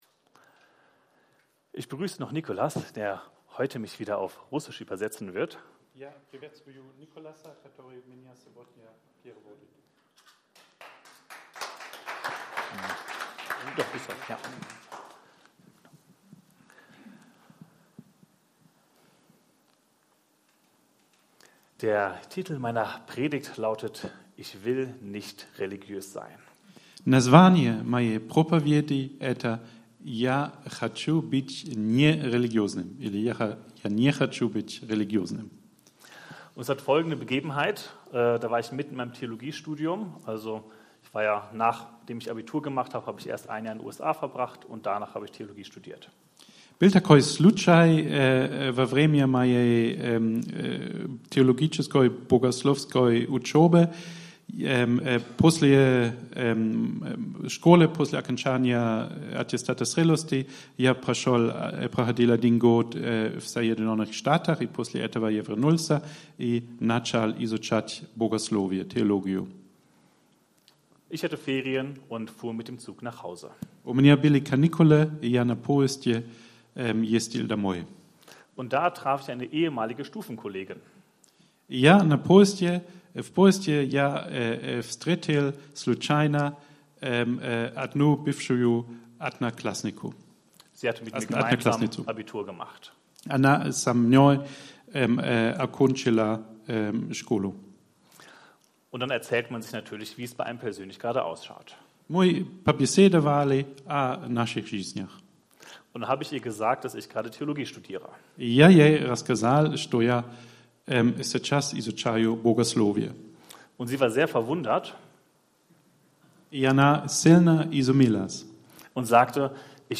Predigten | Willkommen